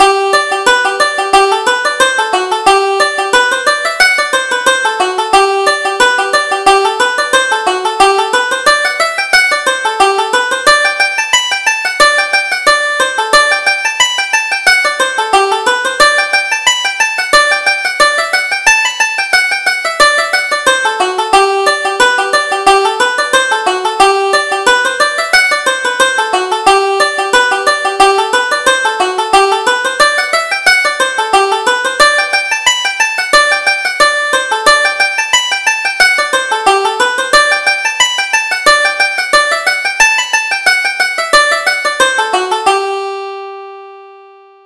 Reel: The Snow on the HIlls